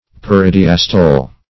Search Result for " peridiastole" : The Collaborative International Dictionary of English v.0.48: Peridiastole \Per`i*di*as"to*le\, n. (Physiol.) The almost inappreciable time which elapses between the systole and the diastole of the heart.